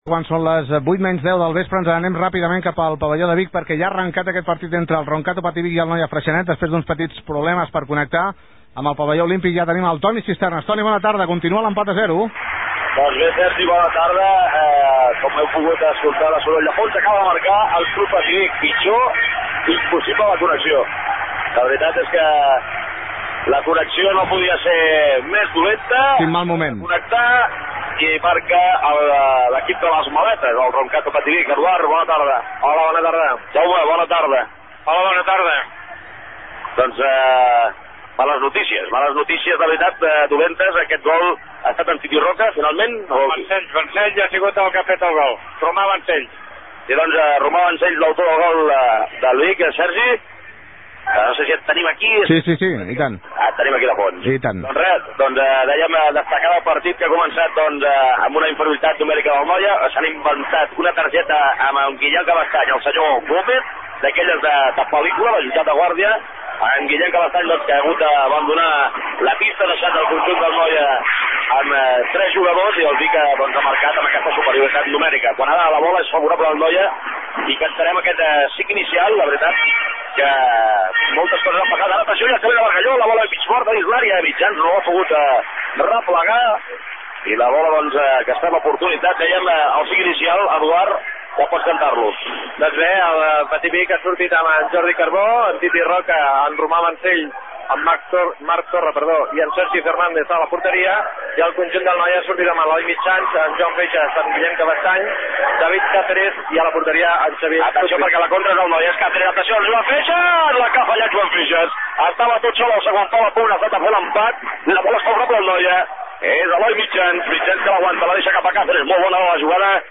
Hora, transmissió del partit d’hoquei patins masculí entre el Roncato Patí Vic i el Noia Freixenet . Gol del vic, equips, narració de les jugades i valoracions de les jugades dels especialistes
Esportiu
FM